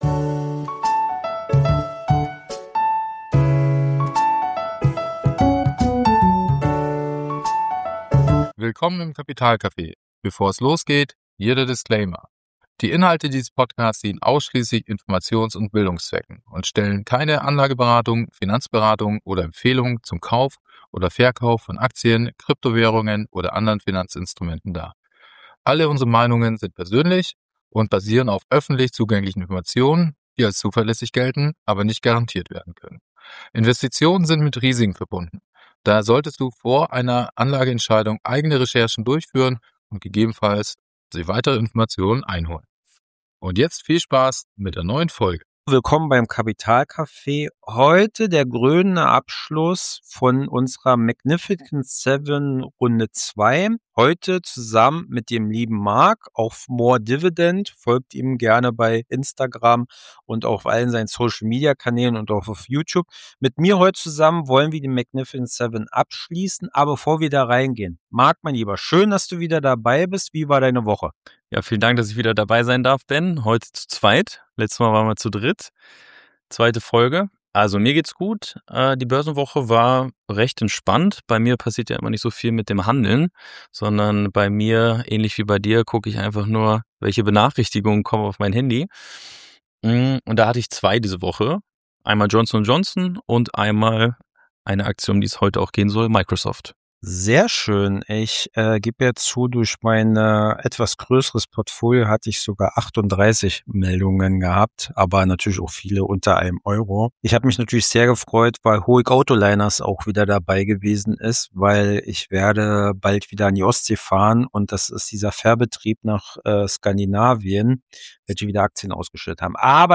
Zwei Dividendenjäger diskutieren die Tech-Giganten. Mit klarem Fokus: Lohnen sie sich für die Dividendenkasse oder nicht?